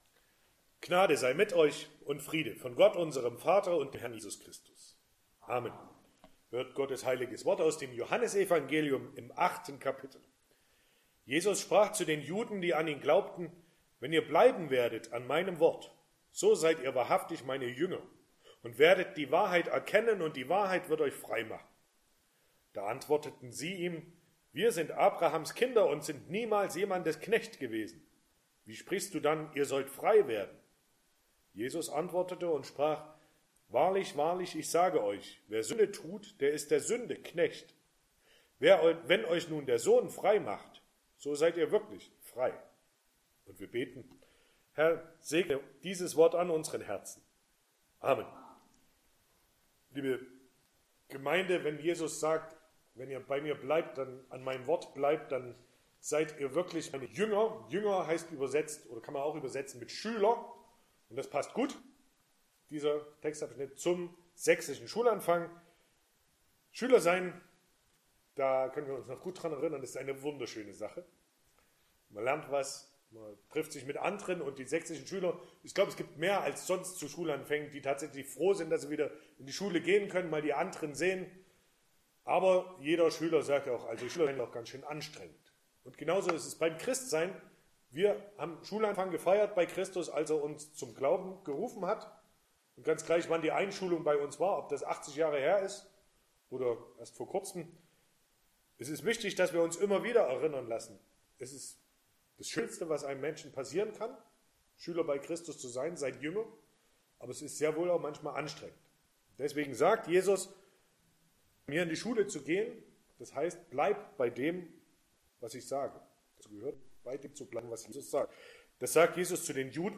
Sonntag nach Trinitatis Passage: Johannes 8,31-36 Verkündigungsart: Predigt « 13.